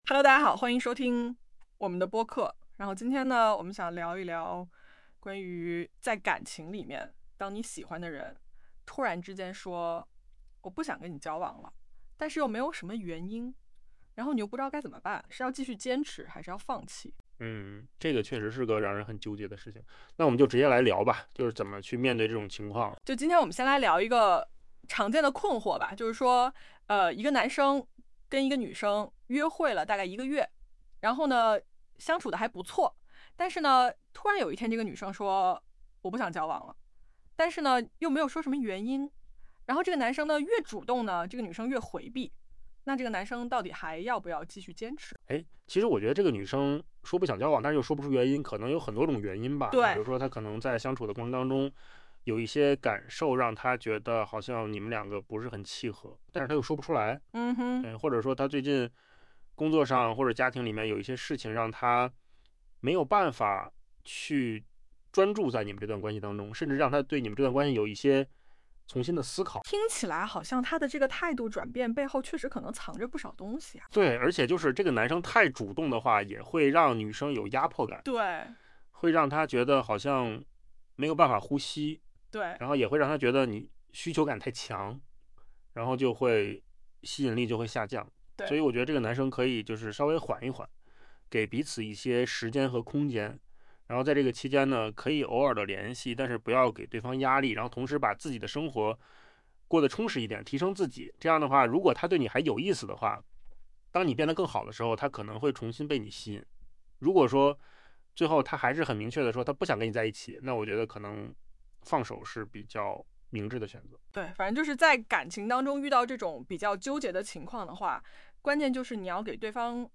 不得不赞叹，扣子空间生成的播客和真人录制的语音效果几乎没有区别了，AI免费打工的图景正成为现实。